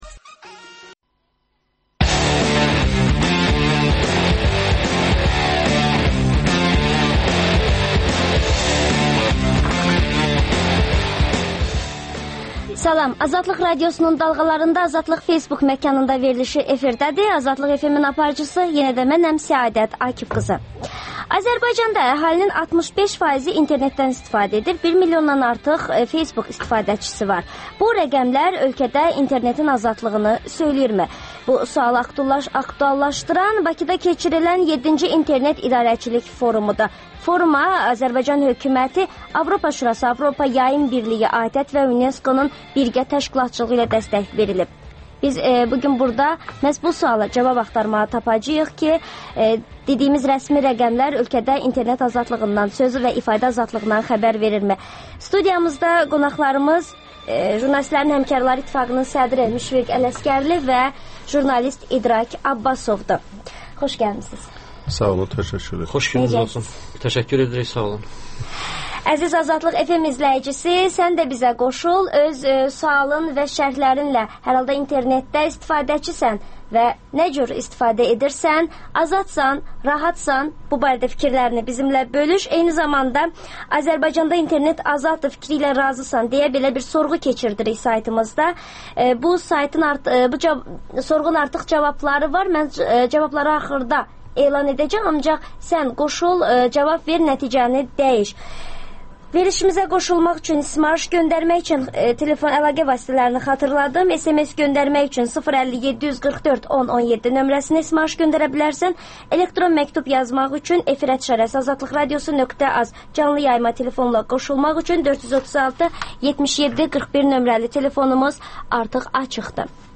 Azadlıq FM - İnternet azadlığı mövzusunda debat